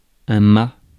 Ääntäminen
Synonyymit bigue Ääntäminen France (Paris): IPA: [œ̃ ma] Tuntematon aksentti: IPA: /mɑ/ Haettu sana löytyi näillä lähdekielillä: ranska Käännös 1. мачта {f} (máčta) Suku: m .